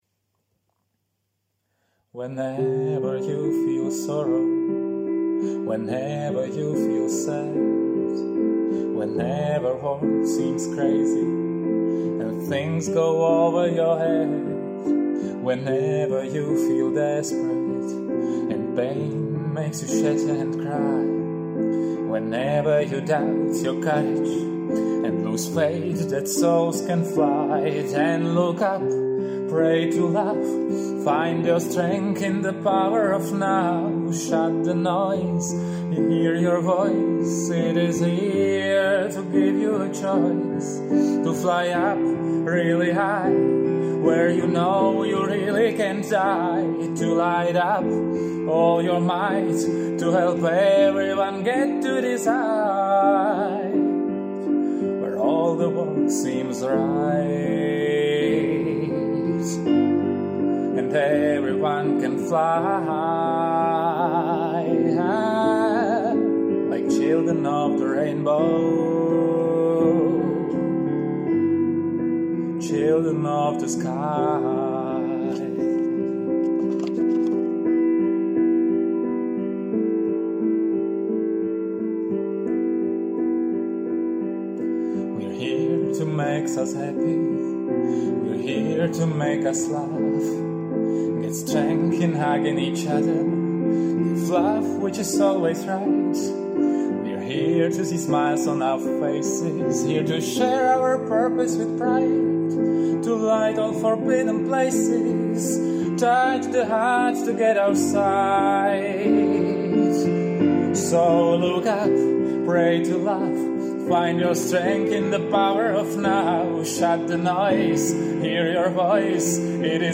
a jako dárek ti posílám tuto nabíjející píseň